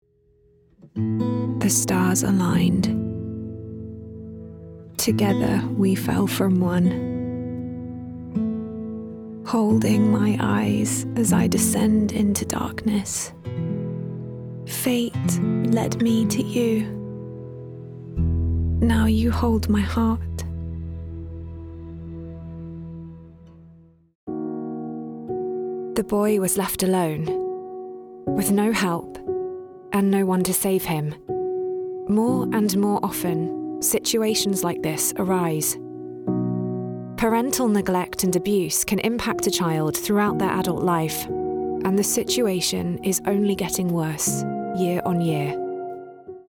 English (British)
Bright, warm, fresh, natural and professional are just some of the ways my voice has been described.